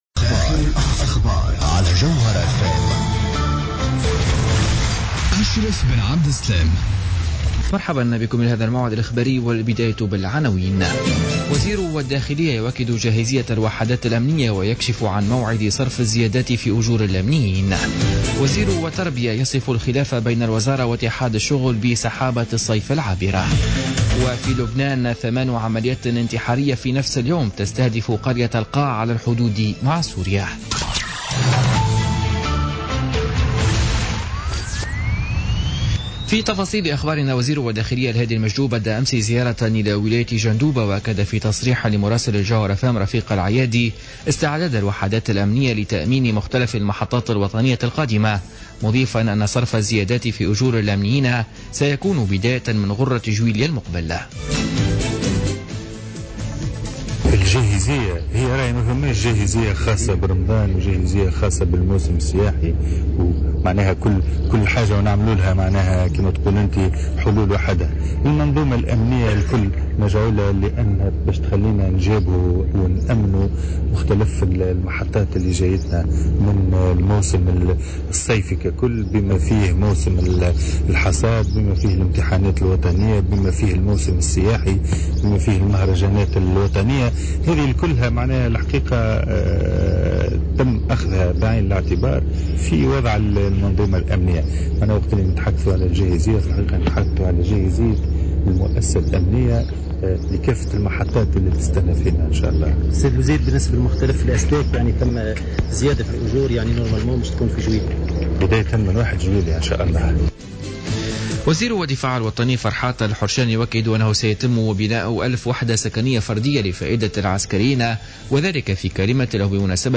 نشرة أخبار منتصف الليل ليوم الثلاثاء 28 جوان 2016